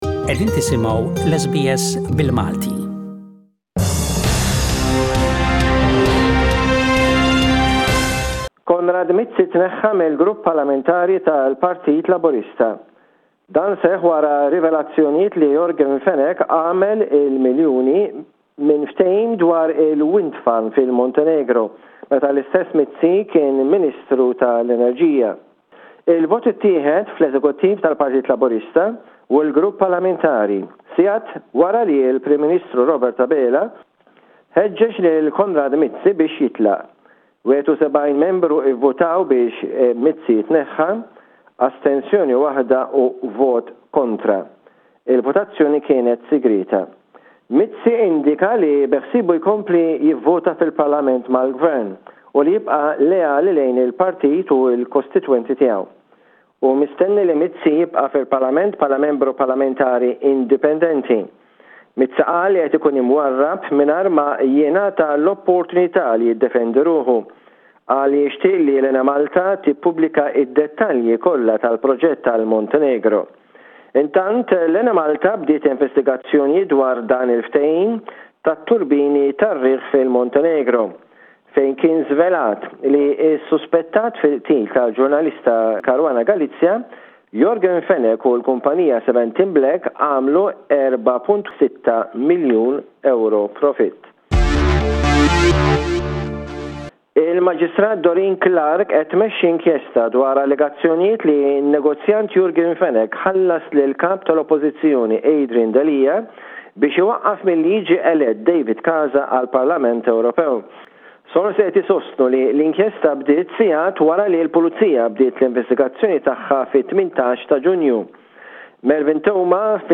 SBS radio correspondent